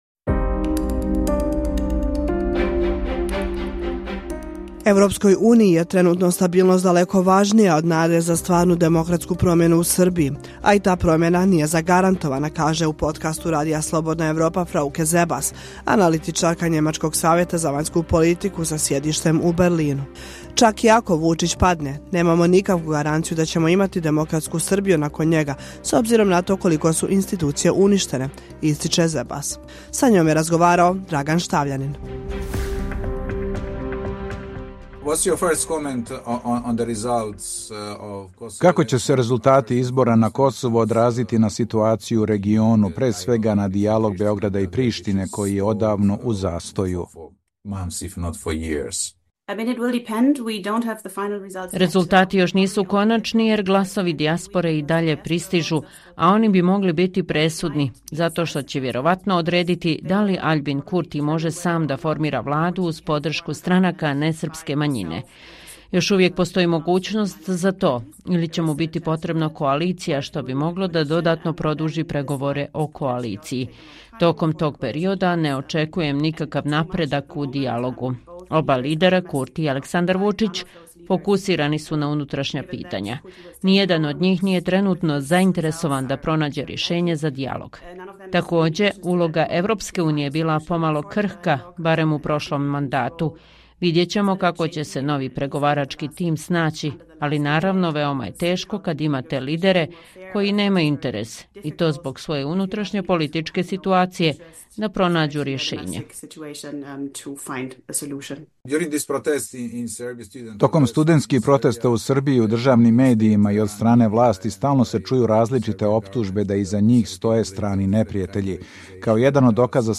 razgovoru za podcast Radija Slobodna Evropa